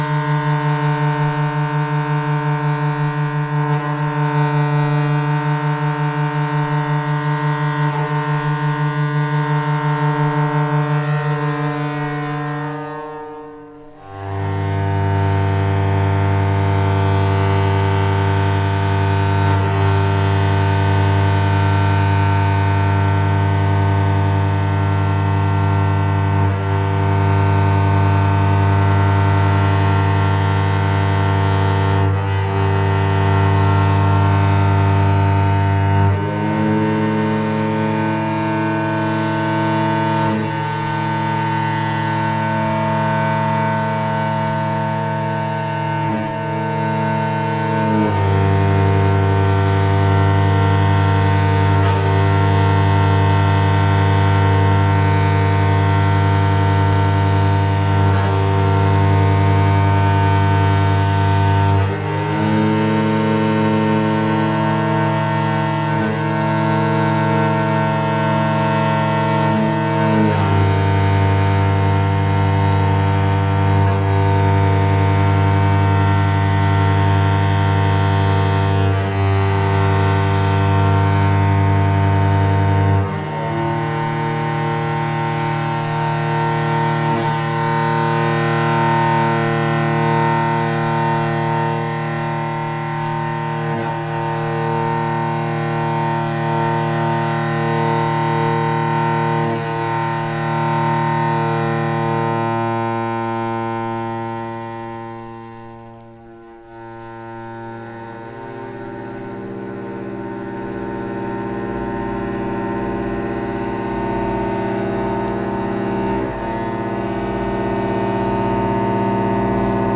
o violoncelo.